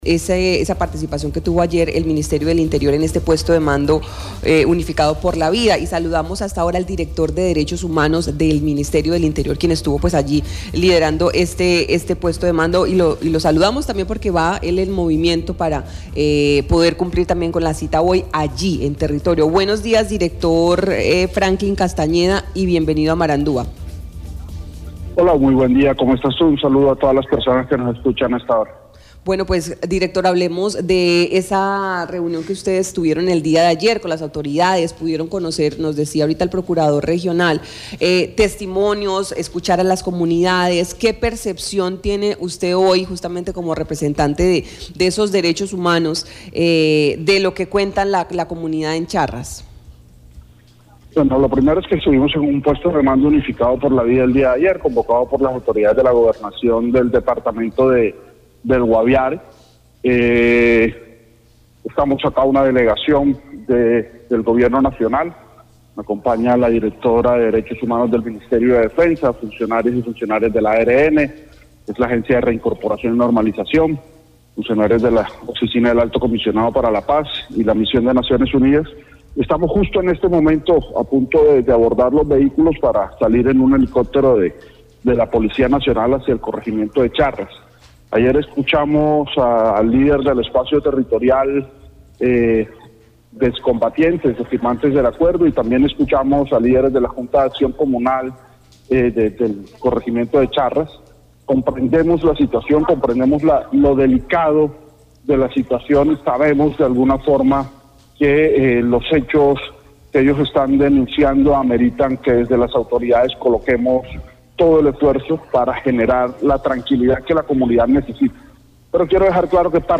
Franklin Castañeda, director de Derechos Humanos del Ministerio del Interior, en una entrevista con Marandua Noticias, se pronunció sobre la instalación del Puesto de Mando Unificado (PMU) por la vida.